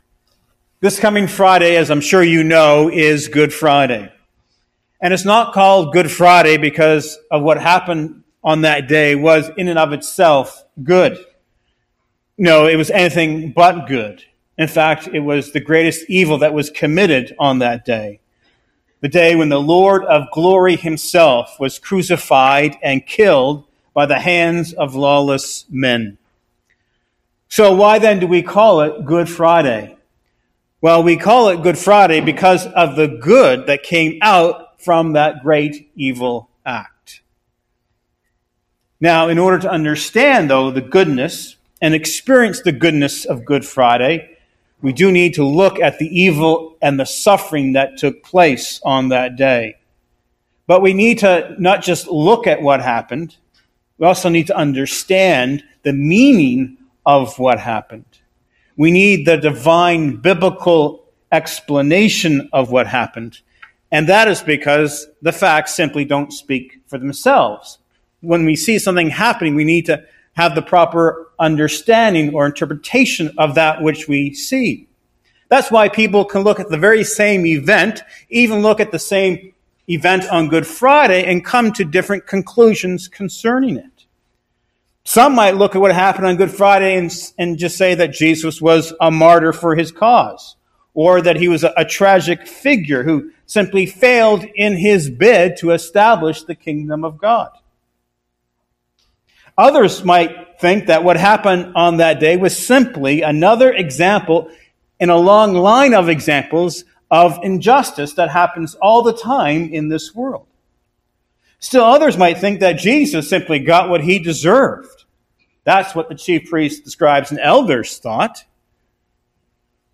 25 minute mp4 sermon follows sermon highlights: